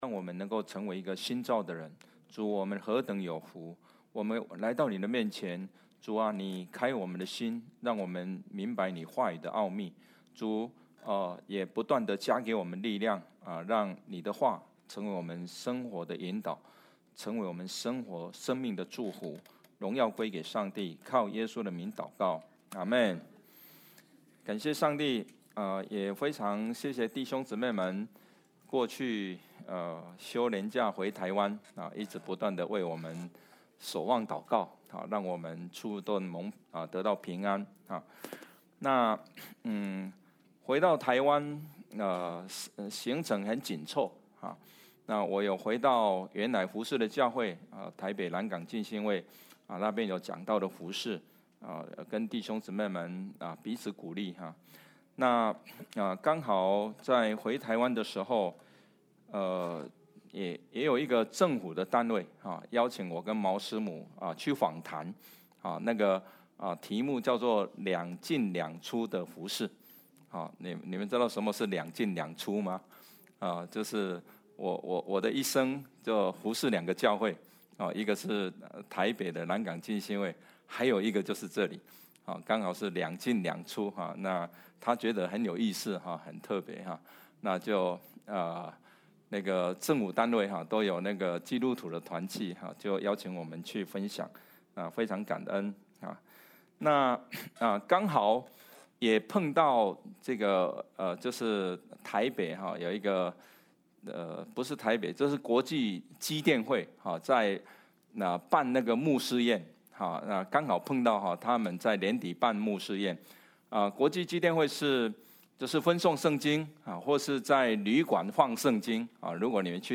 October 26, 2025 作贵重的器皿 Passage: Leviticus 16:29–34 Service Type: 主日证道 Download Files Notes « 乐意奉献的心 过成圣的生活 » Submit a Comment Cancel reply Your email address will not be published.